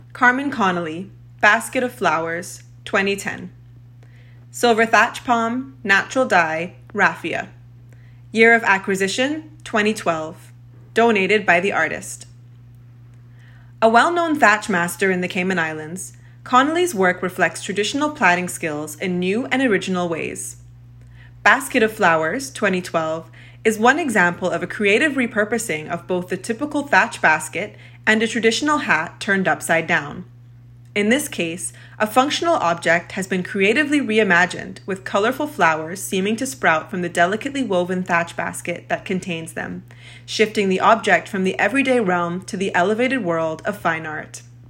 Basket of Flowers Voiceover